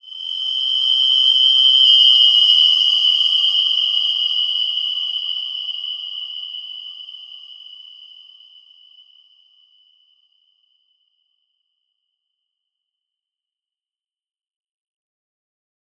Wide-Dimension-G6-f.wav